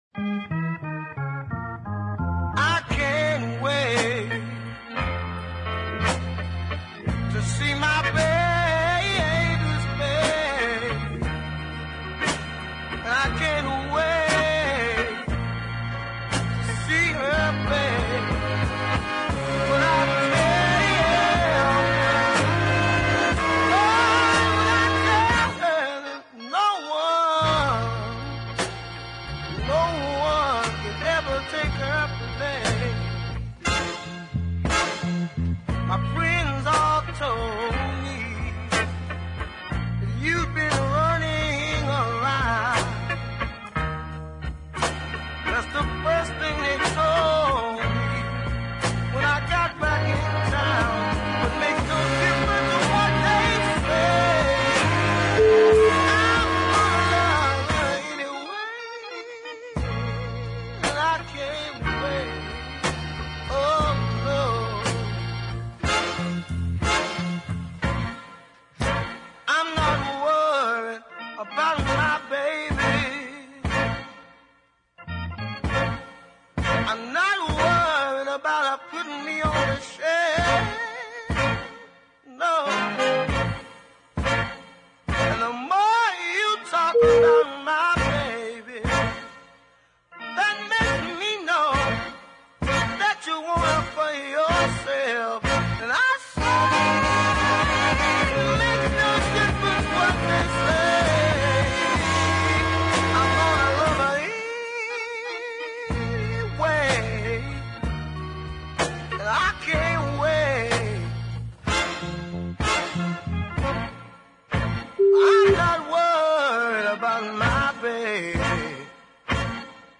Check out the beautifully arranged horns as well.